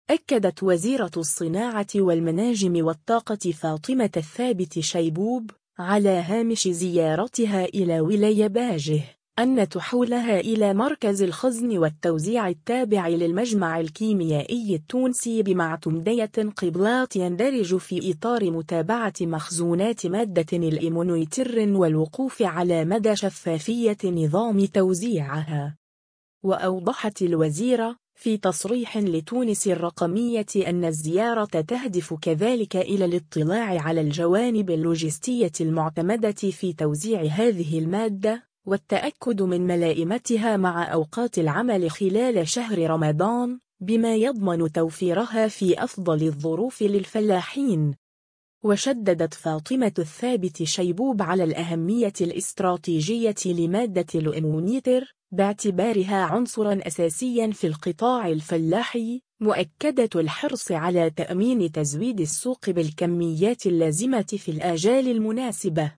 وأوضحت الوزيرة، في تصريح لتونس الرقمية أن الزيارة تهدف كذلك إلى الاطلاع على الجوانب اللوجستية المعتمدة في توزيع هذه المادة، والتأكد من ملاءمتها مع أوقات العمل خلال شهر رمضان، بما يضمن توفيرها في أفضل الظروف للفلاحين.